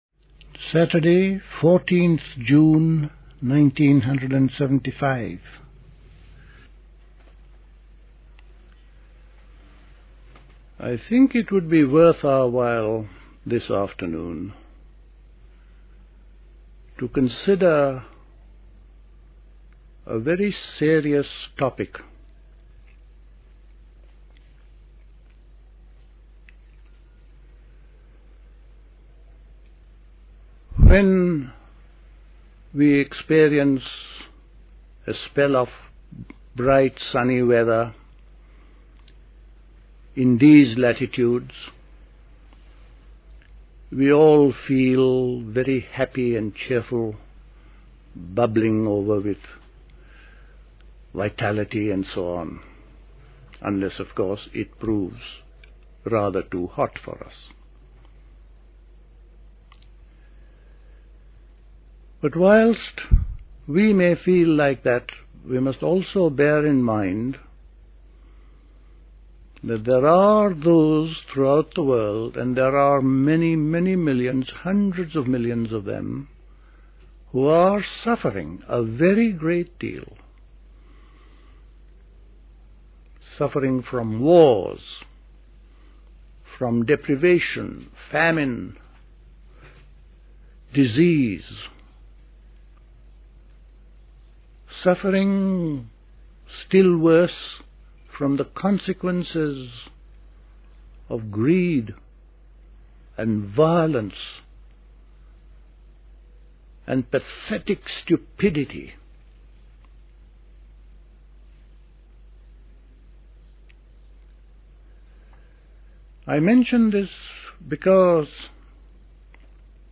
A talk
at Dilkusha, Forest Hill, London on 14th June 1975